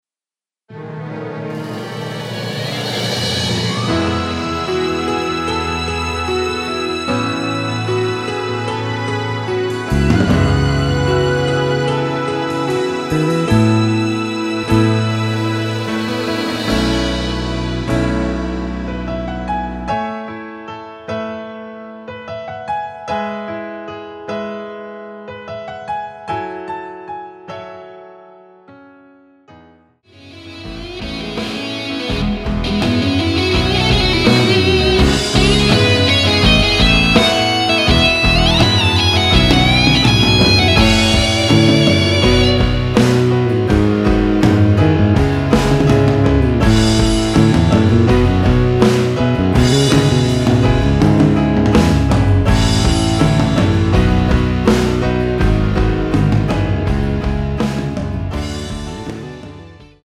멜로디 MR이란
앞부분30초, 뒷부분30초씩 편집해서 올려 드리고 있습니다.
중간에 음이 끈어지고 다시 나오는 이유는